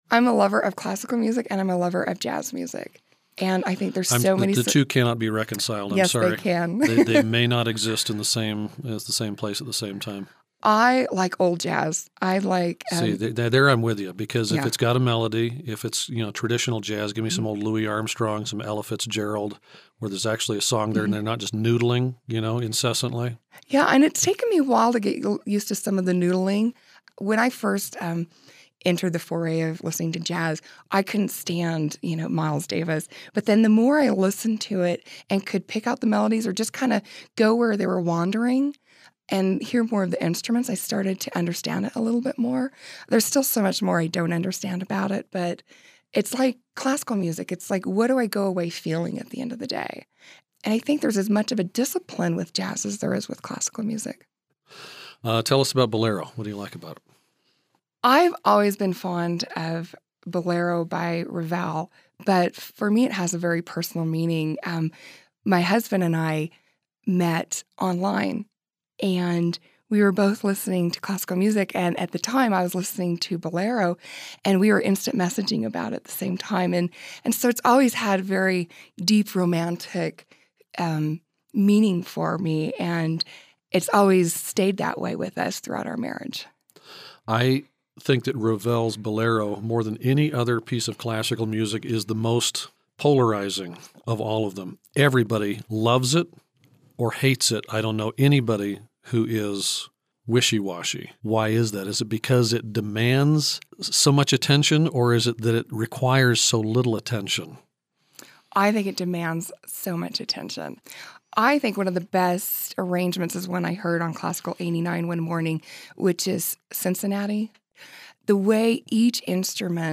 The interview recording studio at Classical 89.
Listen to my interview as I explain why I’m so in love with this piece. We talk a little about jazz and I confessed that it took me awhile to appreciate Miles Davis, but I do now and in a very big way.